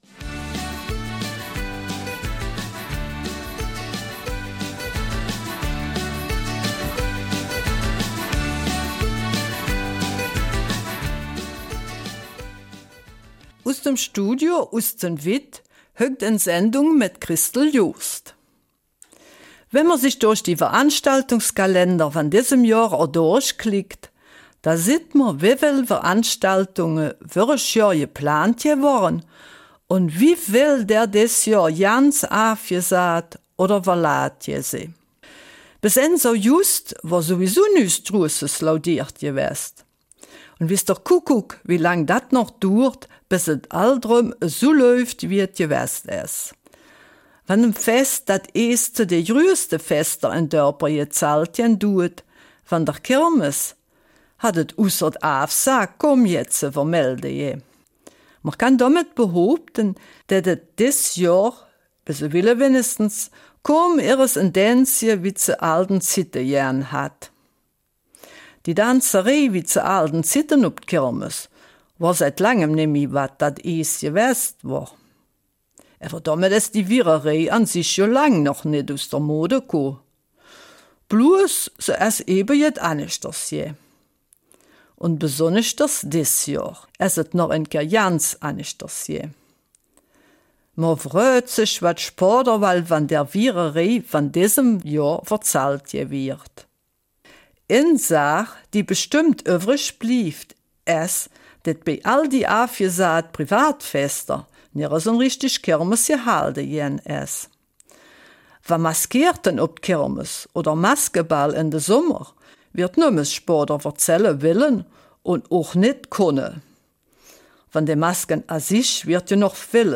Eifeler Mundart: 50 Jahre Saal Concordia